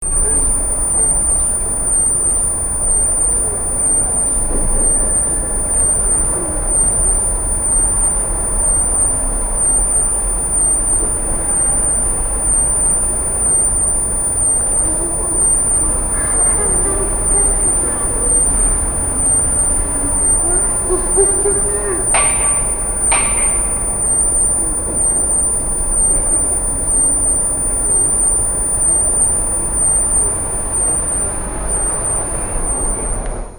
ピッチダウン２
実際はもうっと高い「キーン」と言う音に聞こえます
mosquito_sound3.mp3